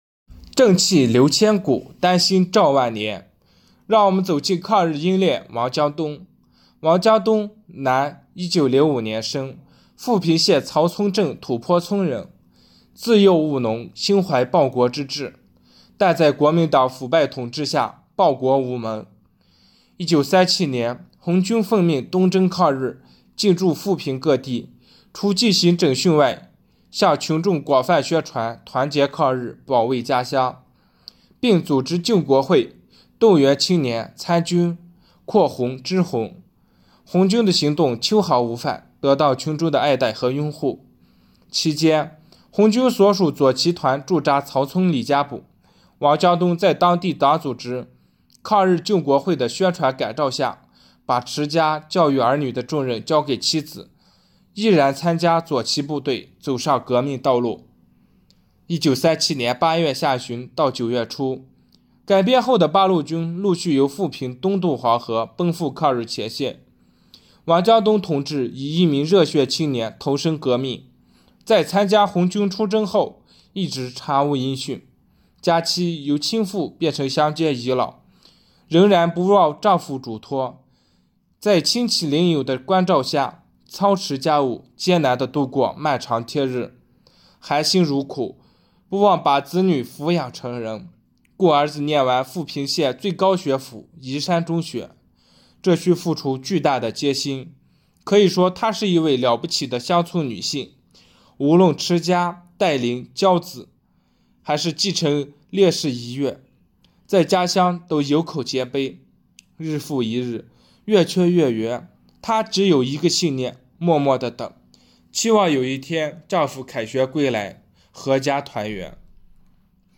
【红色档案诵读展播】抗日英烈王江东